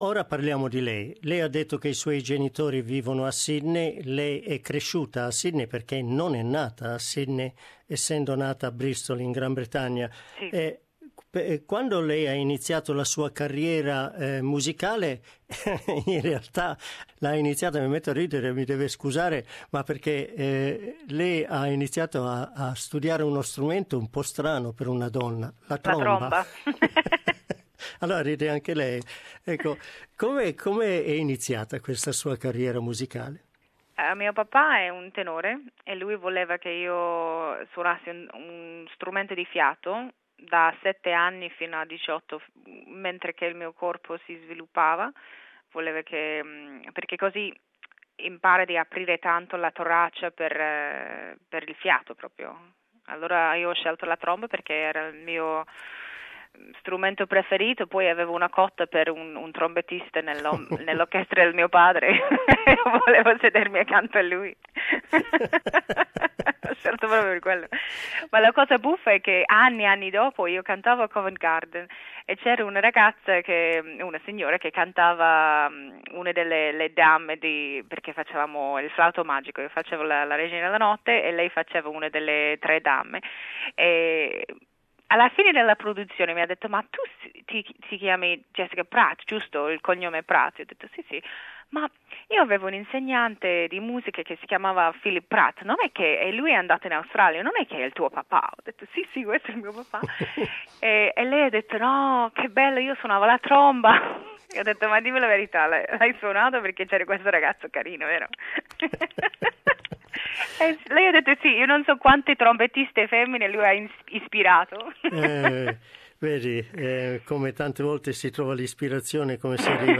La settimana scorsa abbiamo iniziato la nostra conversazione con il soprano australiano Jessica Pratt che vive da anni a Fiesole, nei pressi di Firenze.